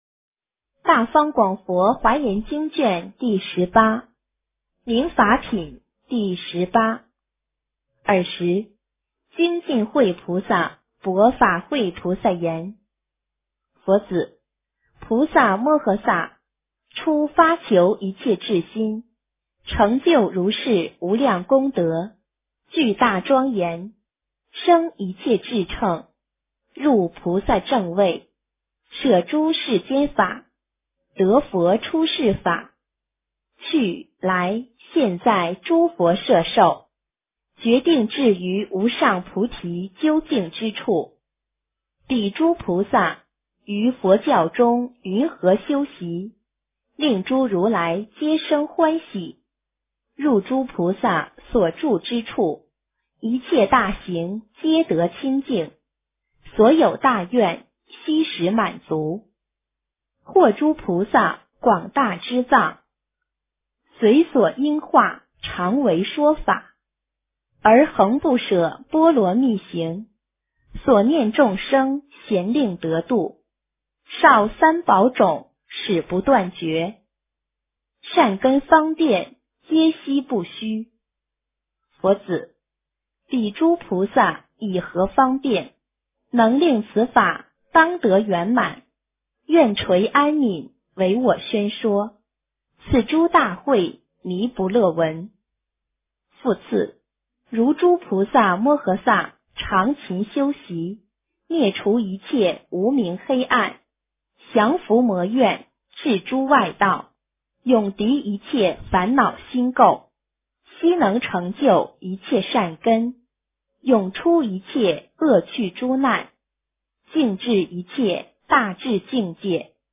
华严经18 - 诵经 - 云佛论坛